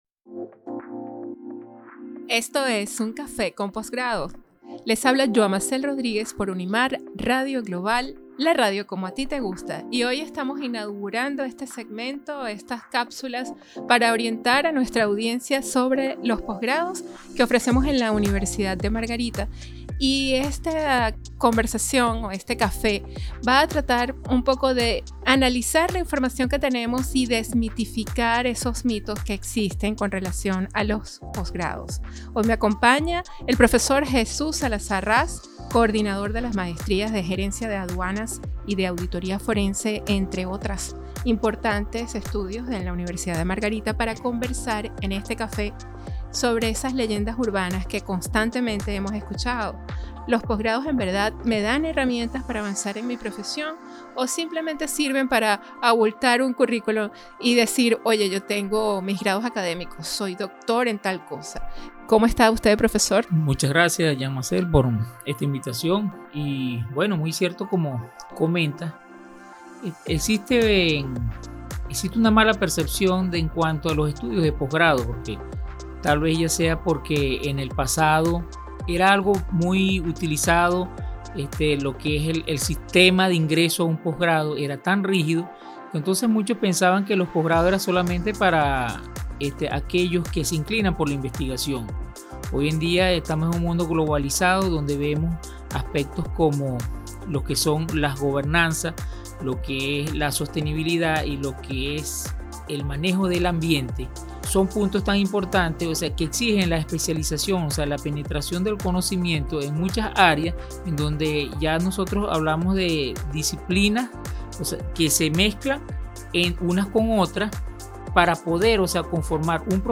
Un café con postgrado es un programa de radio breve, dinámico y ameno que explora las ideas, creencias, miedos y limitaciones que enfrentan las personas al considerar un postgrado. A través de conversaciones relajadas con expertos, profesores y coordinadores de las diferentes maestrías y programas de postgrado de la Universidad de Margarita, se pretende analizar las fortalezas, potencialidades y oportunidades que ofrece esta institución.